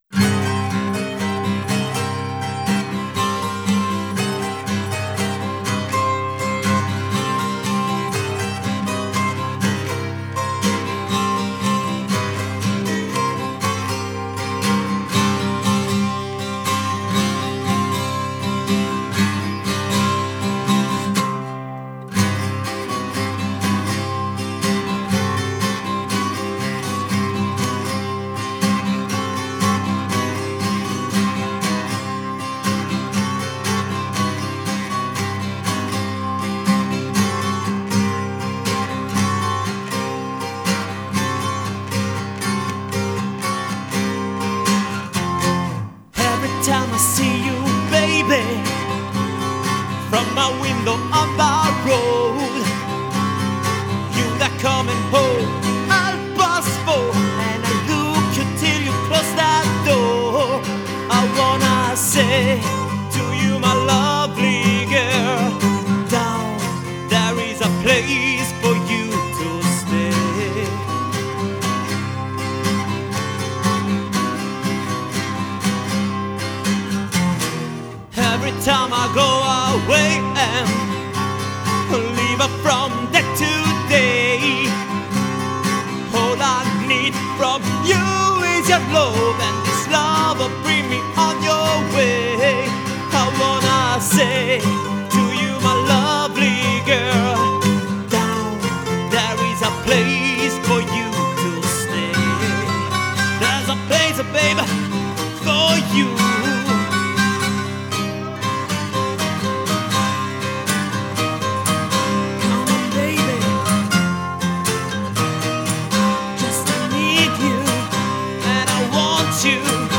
giocata con l’acustica in accordatura aperta e col mandolino